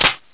assets/ctr/nzportable/nzp/sounds/weapons/tesla/clipin.wav at e9d426c10d868c5ff3c693c1faa597ec4a549cf4
PSP/CTR: Also make weapon and zombie sounds 8bit